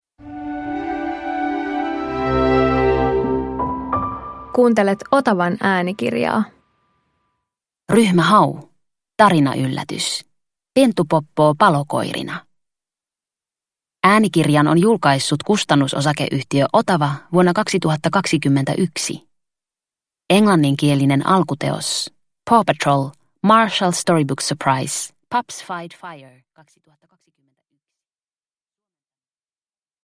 Ryhmä Hau - Pentupoppoo palokoirina – Ljudbok – Laddas ner